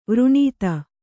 ♪ vrṇīta